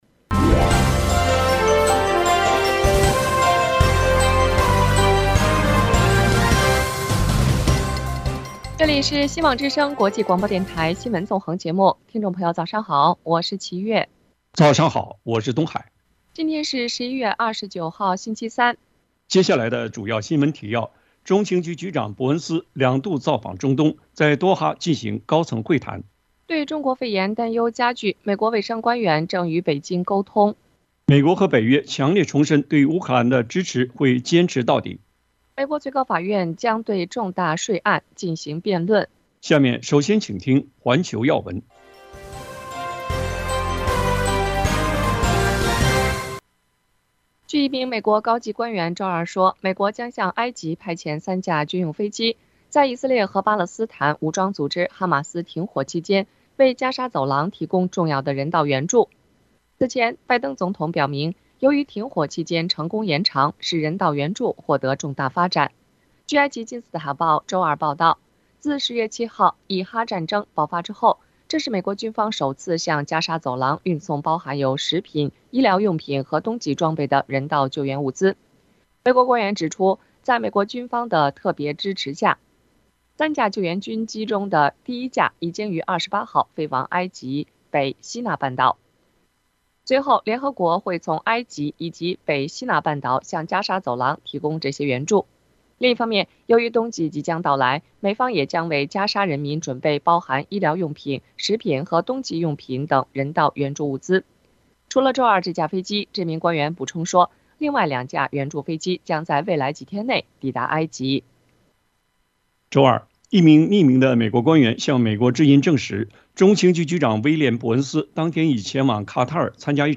阿根廷准总统就职前先访美 预期与中国关系降温【晨间新闻】